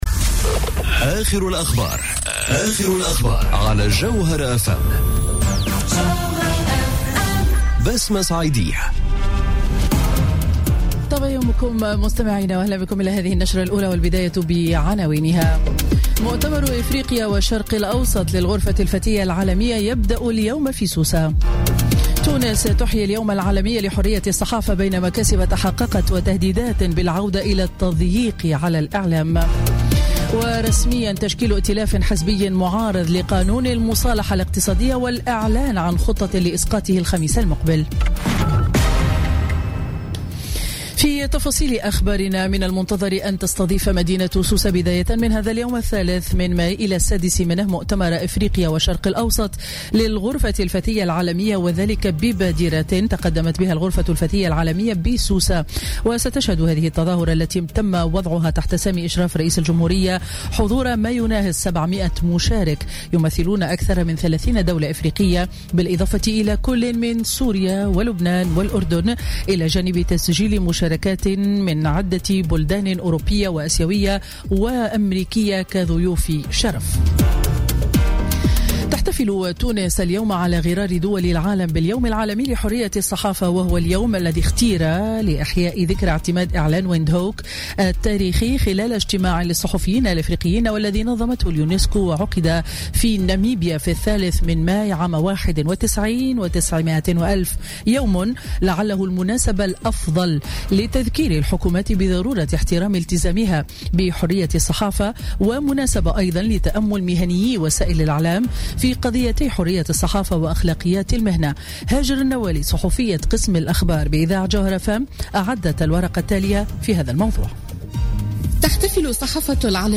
نشرة أخبار السابعة صباحا ليوم الإربعاء 3 ماي 2017